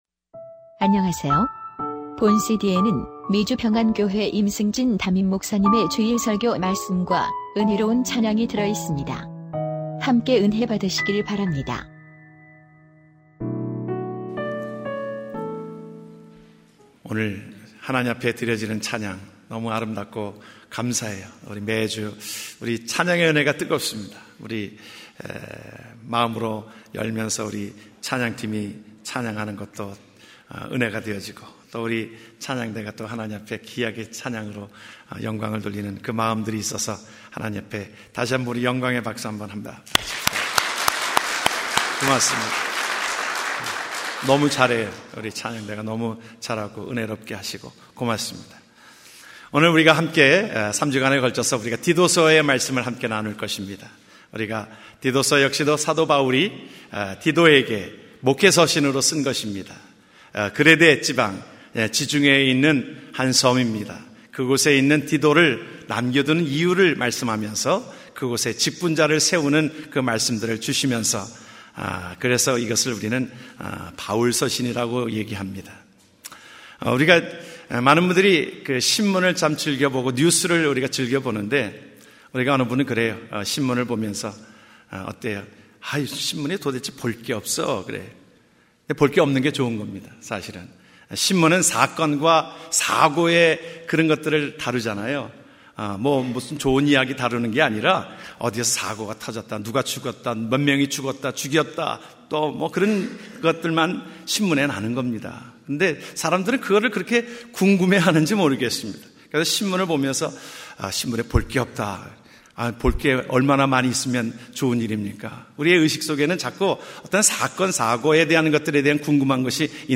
2015년3월8일 주일설교 우리는 빛이요 소금입니다. 디도서 1장5절-9절